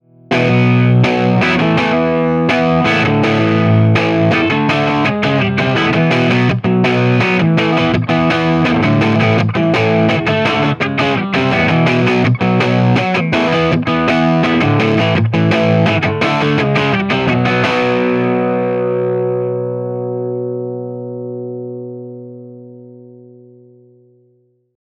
18 Watt v6 - EL84 Dirty Marshall G12T-75
Note: We recorded dirty 18W tones using both the EL84 and 6V6 output tubes.